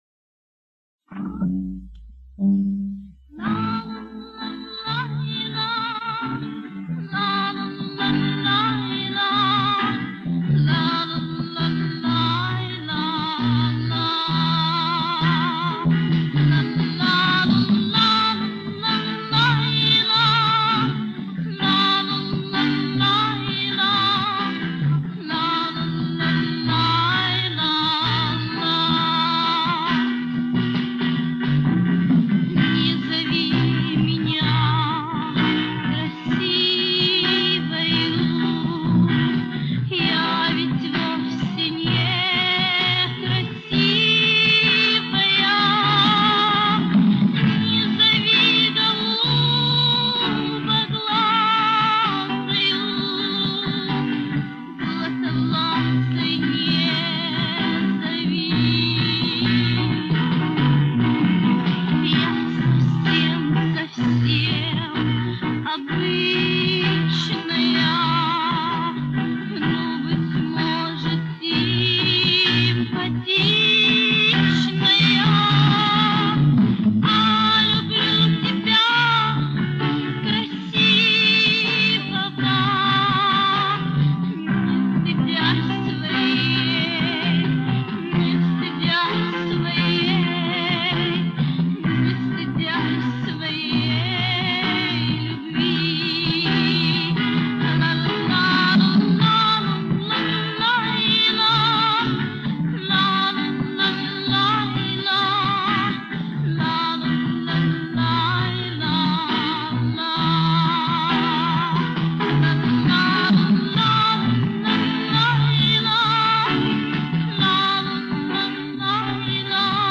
Качество, правда,..